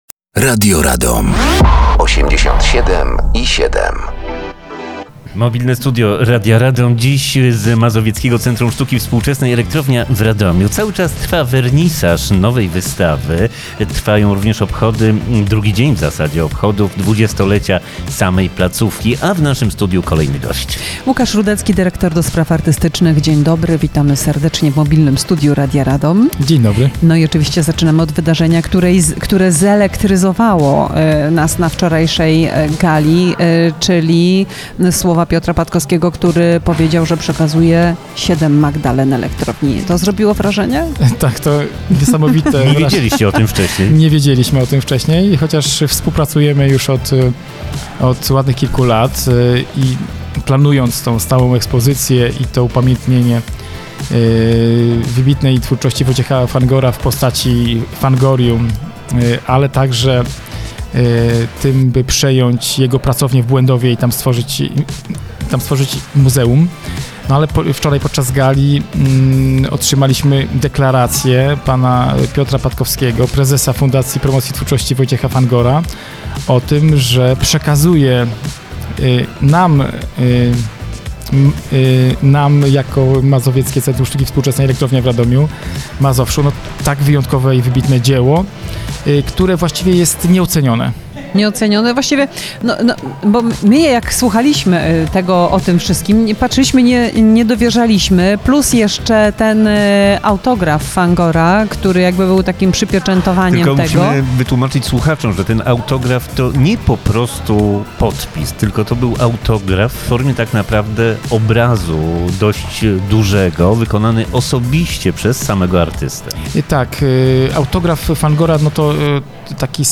Mobilne Studio Radia Radom dzisiaj w Mazowieckim Centrum Sztuki Współczesnej Elektrownia w Radomiu.